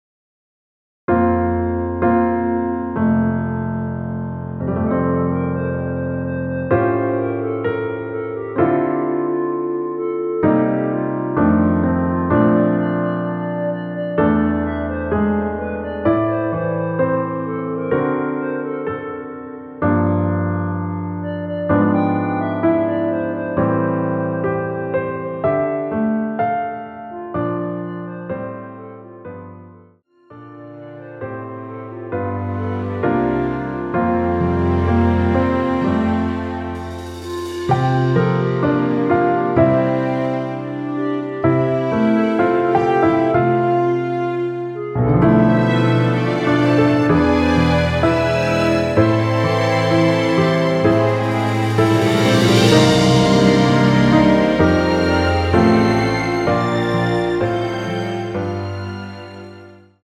전주 없이 시작하는 곡이라 노래하기 편하게 전주 1마디 만들어 놓았습니다.(미리듣기 확인)
원키에서(-1)내린 (1절앞+후렴)으로 진행되는 멜로디 포함된 MR입니다.
앞부분30초, 뒷부분30초씩 편집해서 올려 드리고 있습니다.